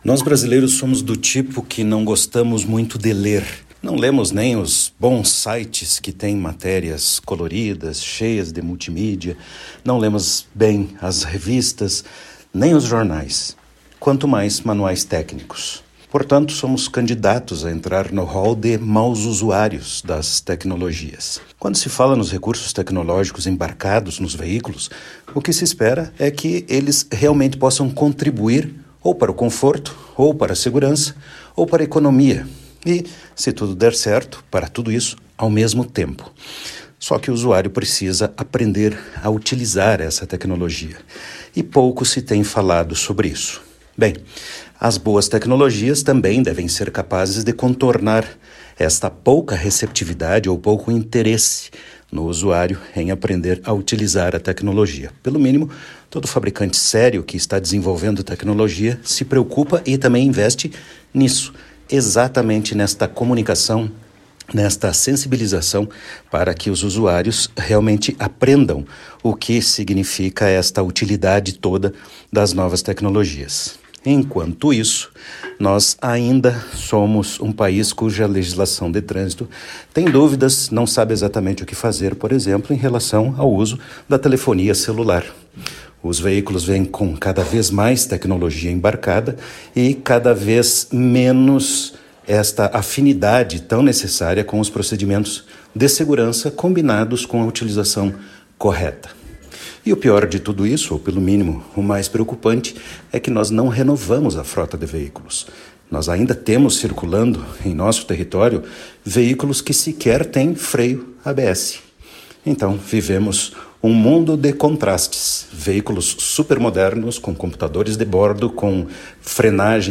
Ouça o áudio completo do especialista.